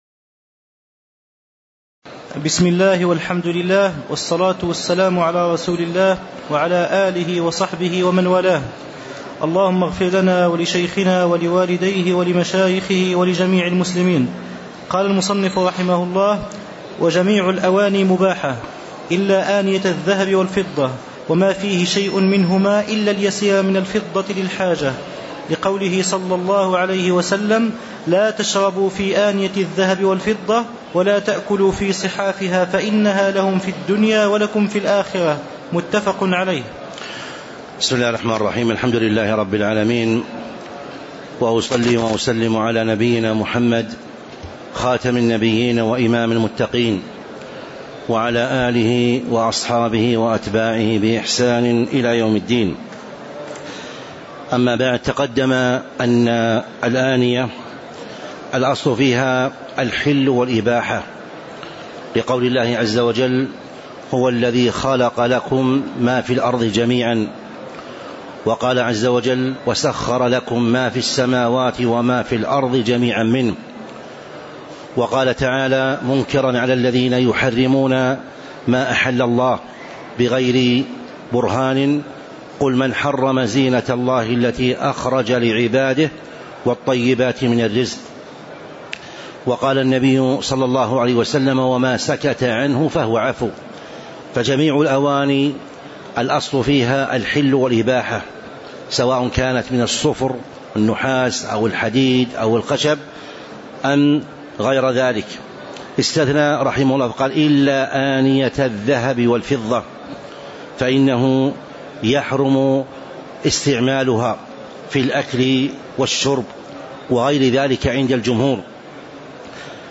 تاريخ النشر ٦ صفر ١٤٤٥ هـ المكان: المسجد النبوي الشيخ